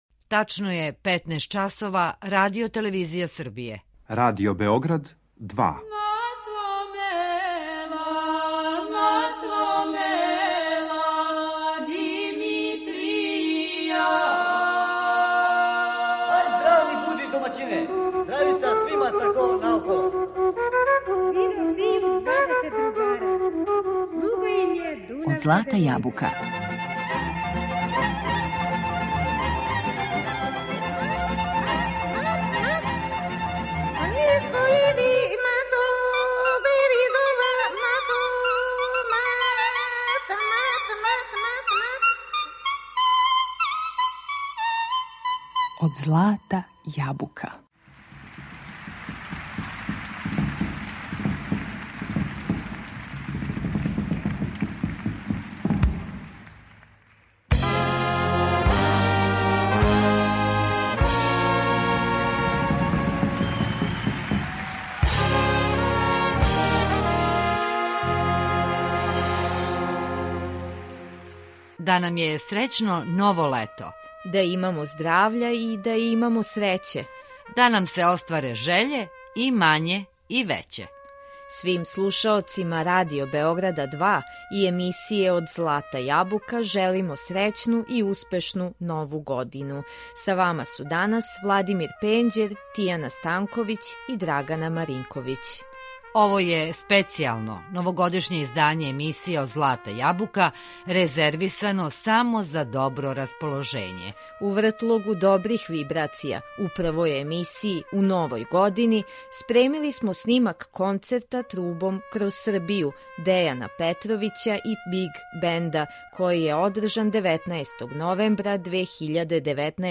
који је одржан 19. новембра 2019. године у Сава центру.
дувачки оркестар